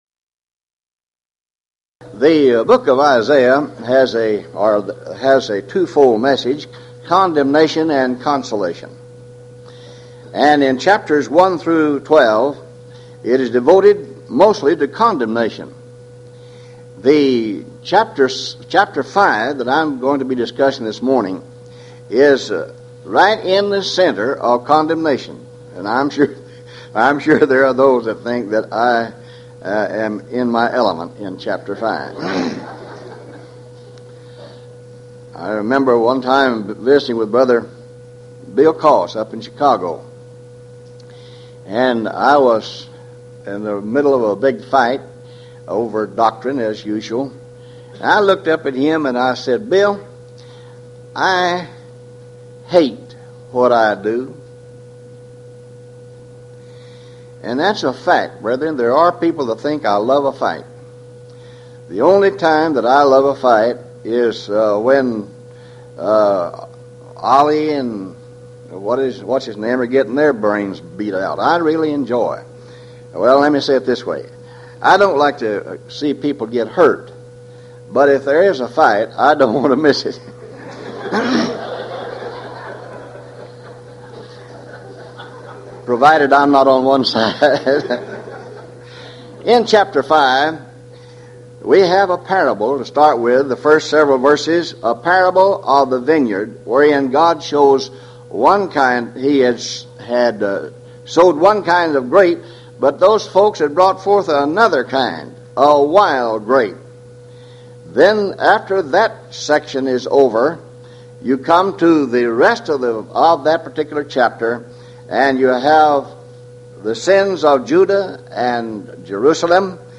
Series: Houston College of the Bible Lectures